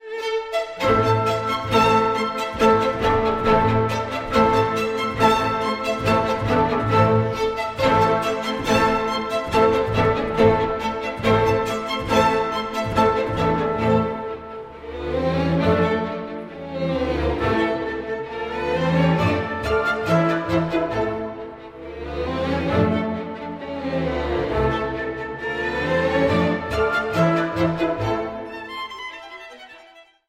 violin
At just 19 years old, Mozart wrote this virtuosic and sparkling concerto for himself to play (even though it wasn’t his primary instrument!).